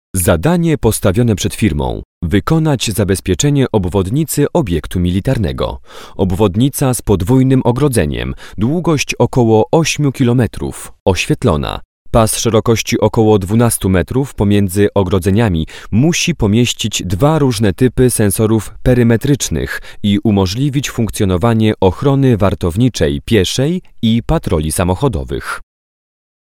Professioneller polnischer Sprecher für TV/Rundfunk/Industrie.
Sprechprobe: Sonstiges (Muttersprache):